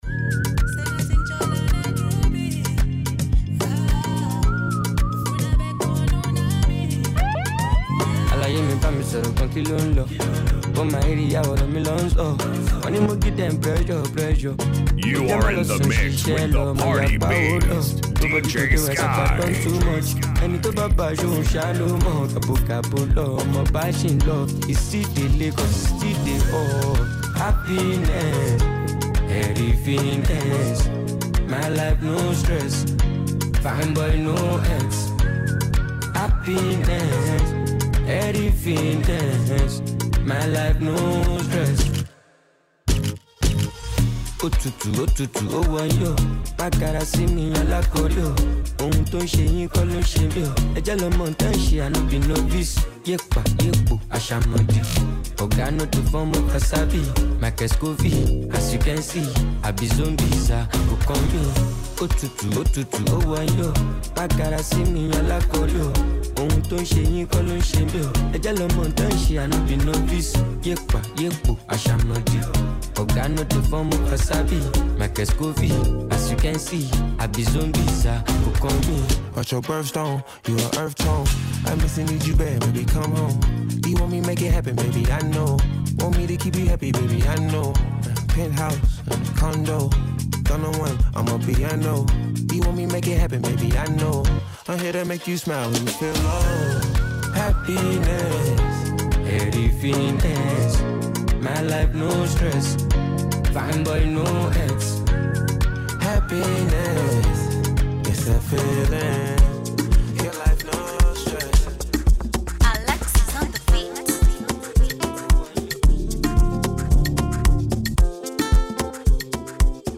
Immerse yourself in the vibrant rhythms of Amapiano